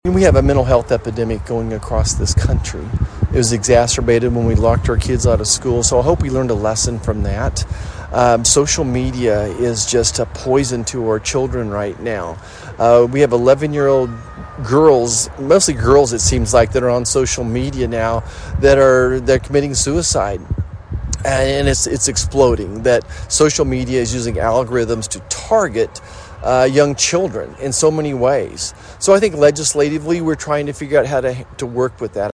In speaking with KVOE News while in Emporia for the re-dedication of the National Memorial to Fallen Educators on Friday, US Senator Roger Marshall says the best way to reduce school violence is to address mental health and social media.